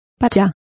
Complete with pronunciation of the translation of pashas.